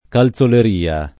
calzoleria [ kal Z oler & a ] s. f.